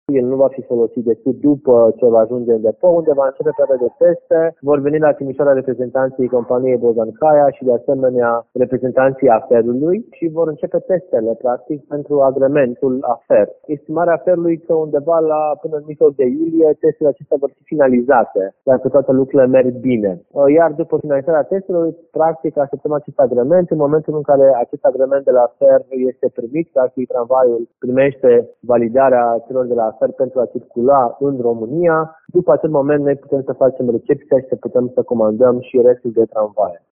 Viceprimarul Ruben Lațcău spune că, după ce trailerul lung de 40 de metri va ajunge la Timișoara, tramvaiul va fi pus pe șine și dus în depou.
ruben-latcau.mp3